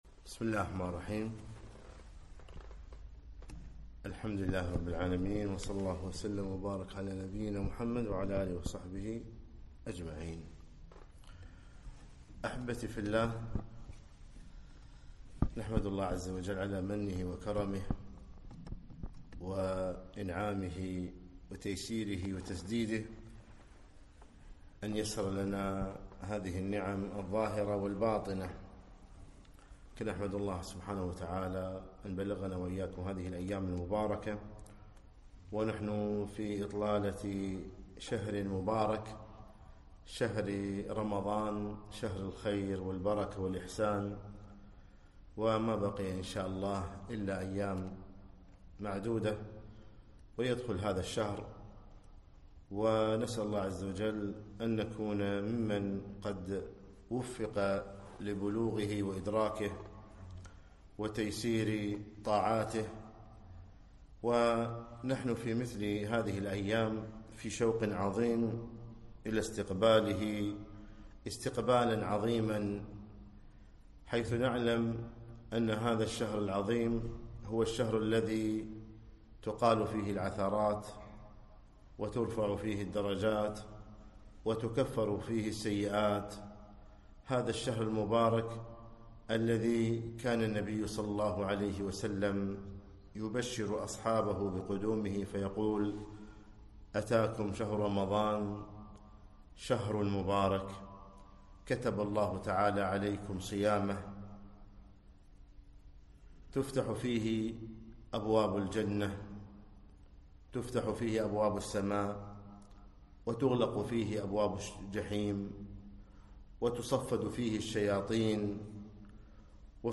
كلمة - يا باغي الخير أقبل